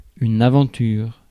Prononciation
Synonymes one-shot Prononciation France: IPA: [a.vɑ̃.tyʁ] Le mot recherché trouvé avec ces langues de source: français Traduction Substantifs 1.